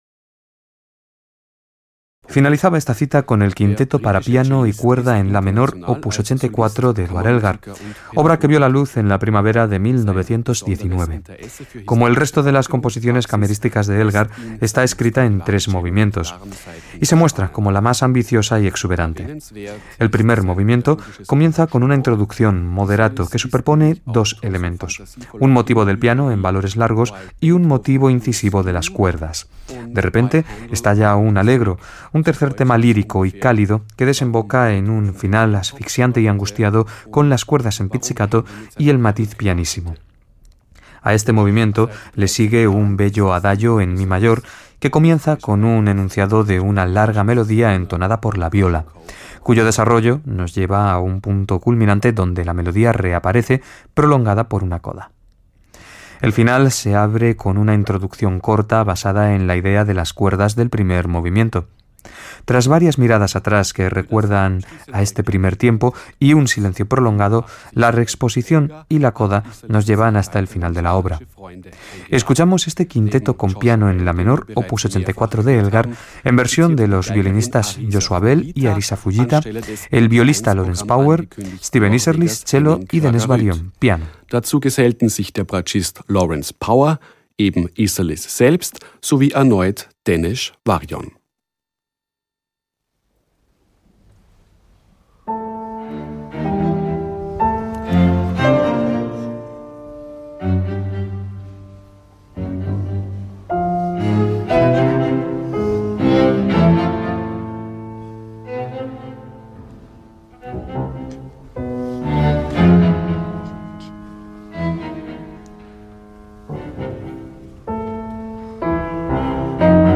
Steven Isserlis And Friends, Featuring Joshua Bell - In Concert From Salzburg - Past Daily Mid-Week Concert
Click on the link here for Audio Player – Steven Isserlis and Friends – Salzburg Festival 2014 – August 7, […]
violins
viola
cello
pianos. The concert was recorded on August 7th by the venerable WDR in Germany and relayed to Radio Nacional España Clasica (the reason why there are German and Spanish announcements going on simultaneously).